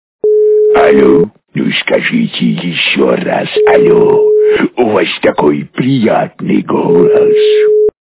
» Звуки » Смешные » Aле, ну скажите еще раз але. - У Вас такой приятный голос
При прослушивании Aле, ну скажите еще раз але. - У Вас такой приятный голос качество понижено и присутствуют гудки.